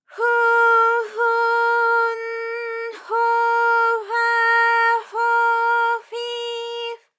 ALYS-DB-001-JPN - First Japanese UTAU vocal library of ALYS.
fo_fo_n_fo_fa_fo_fi_f.wav